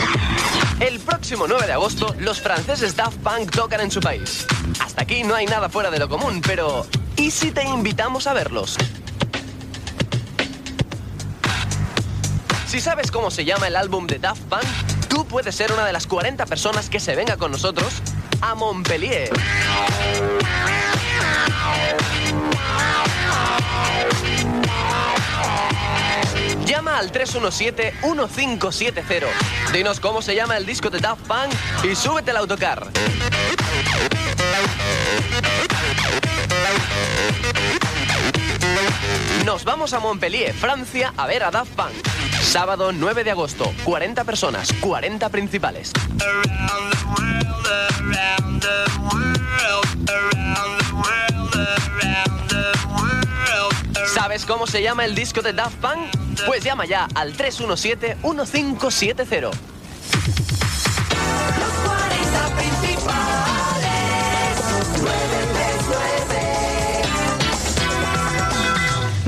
Concurs a la desconnexió de Barcelona i indicatiu.
FM
Gravació realitzada a València.